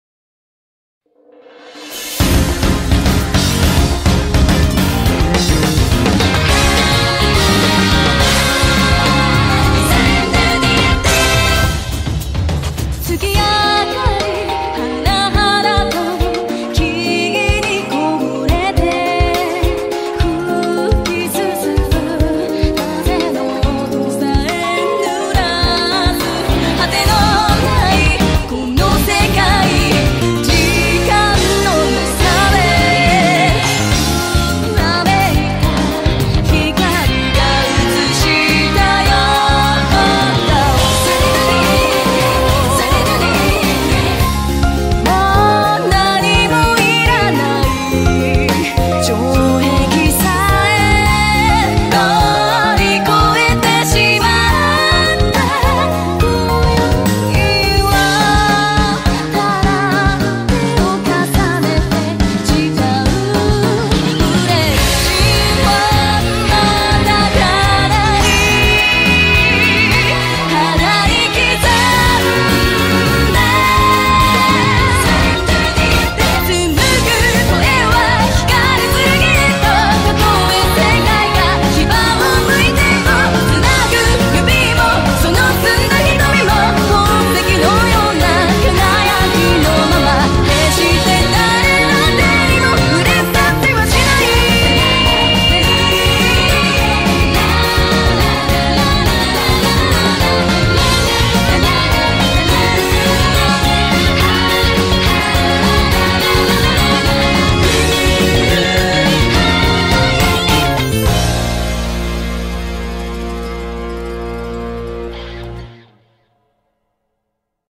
BPM210
Audio QualityPerfect (High Quality)